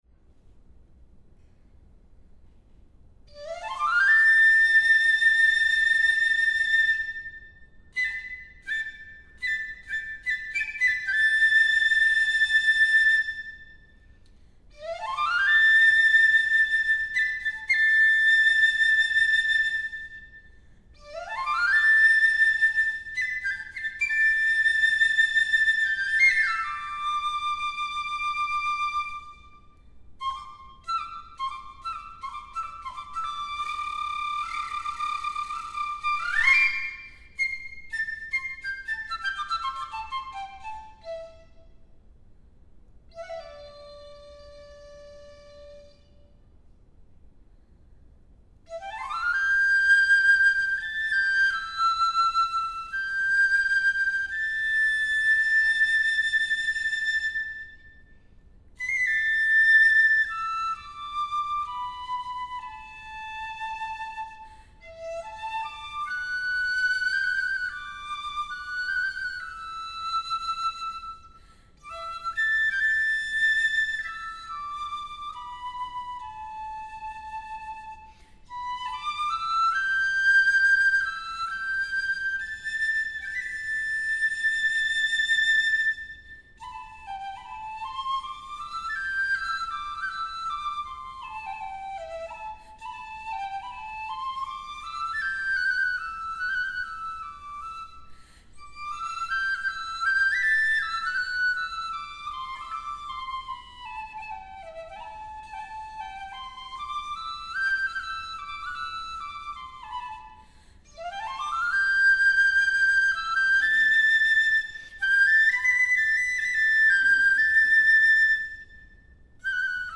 Mimosa (piccolo solo)
This piccolo solo is heavily influenced by the tonal and formal structure of repertoires of Chinese bamboo flute.